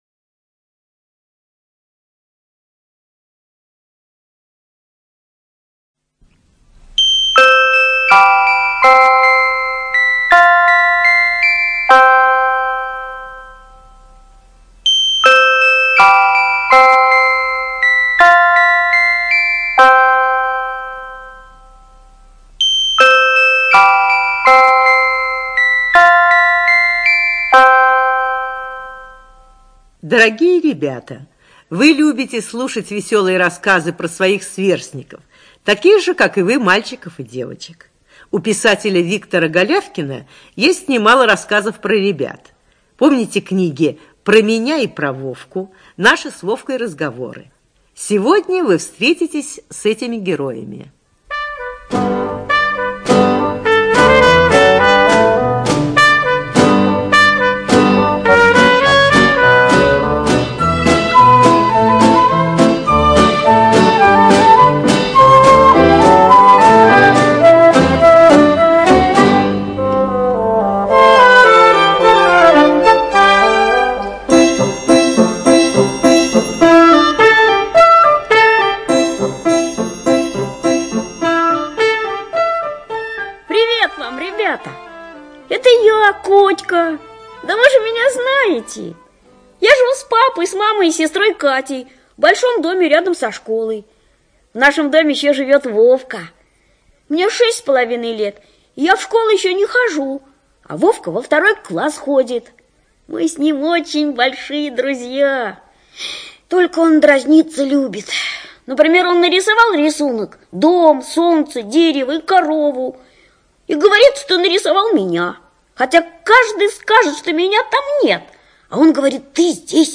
Произведения читают участники группы «Художественное слово», работники учреждения, артисты театров, иные медийные персоны.